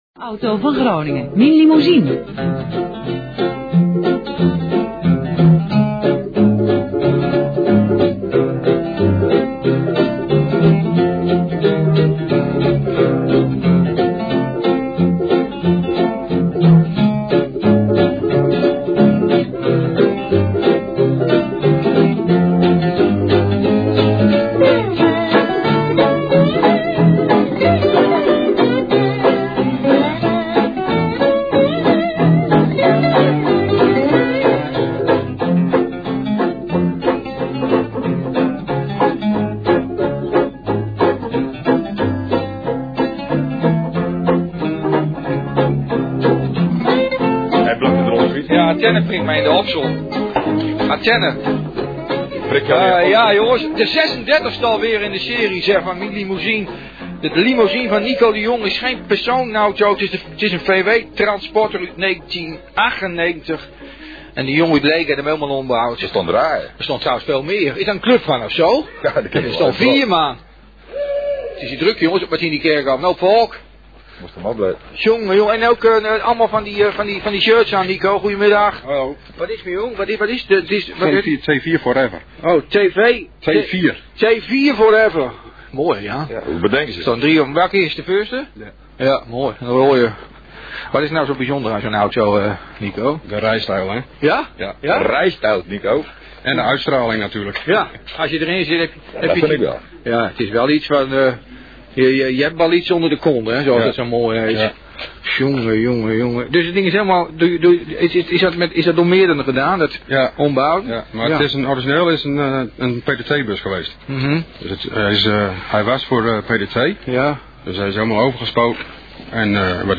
Om 13:30uur is er altijd mien limousine en dan gaan de 2 presentatoren